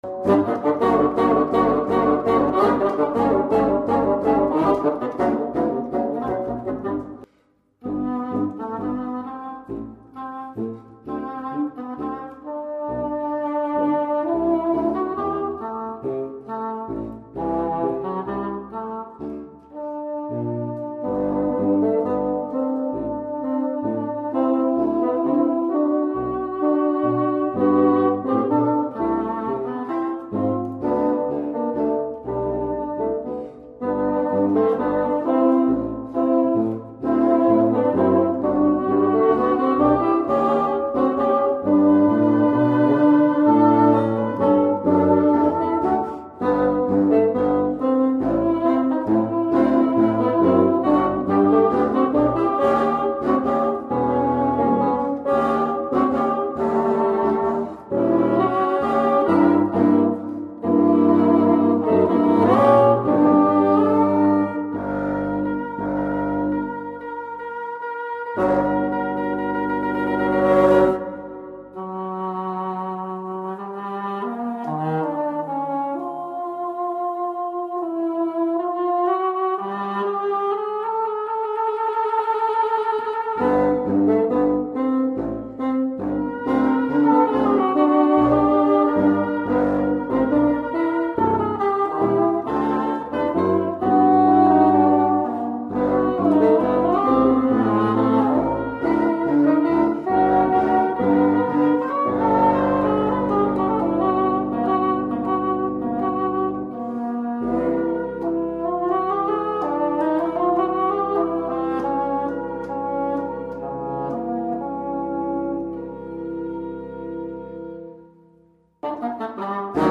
para heckelfone, fagote e contrafagote